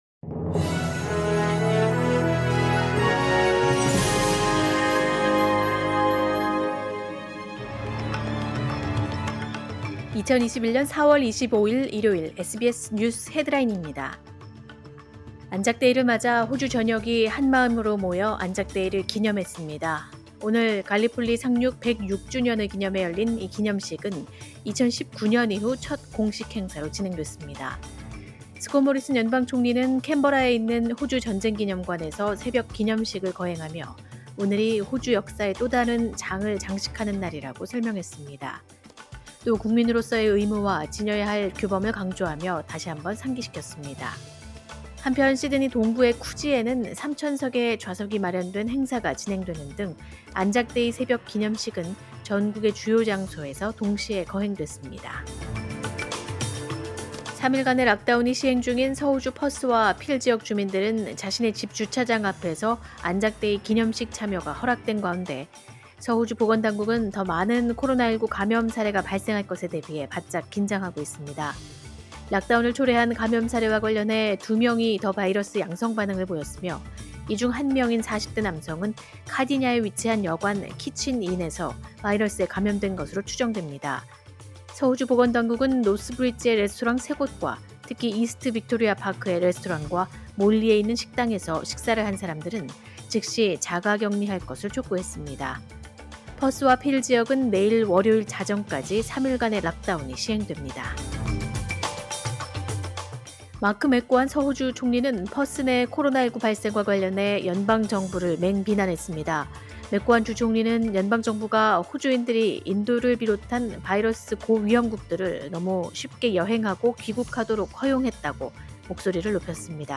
2021년 4월 25일 일요일 SBS 뉴스 헤드라인입니다.